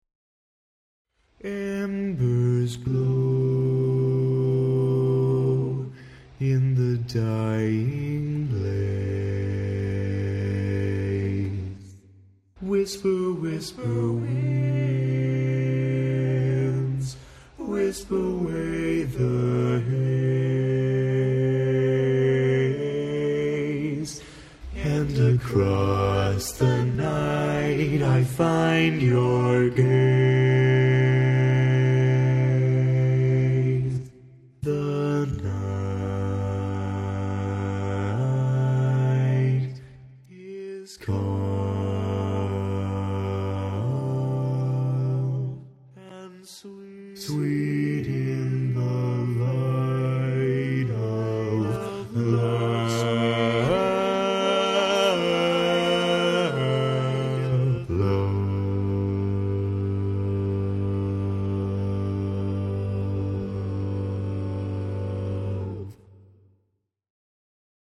Key written in: E♭ Major
Type: Barbershop